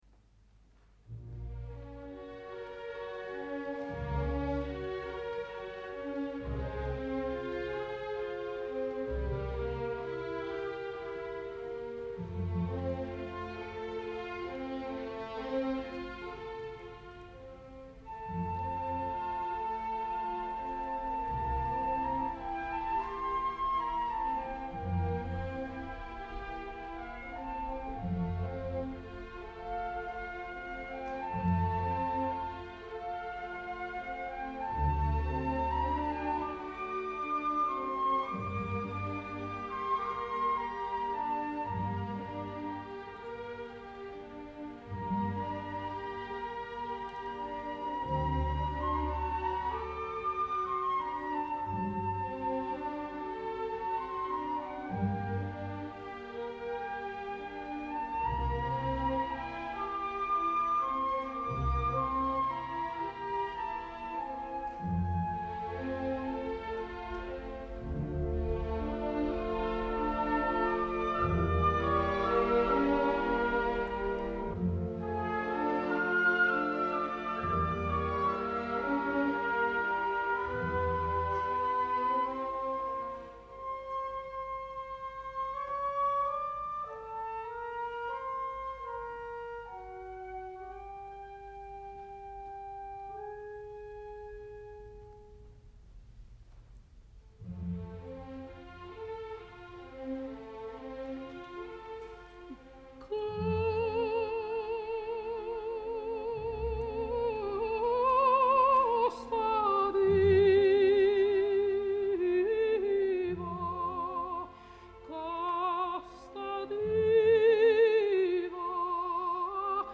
una soprano que no coneixia i que cantava “diferent”
ària
gravada l’any 1957 a Milà